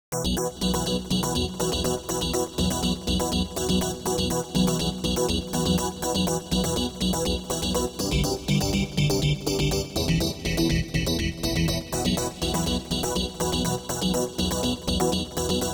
122 BPM Beat Loops Download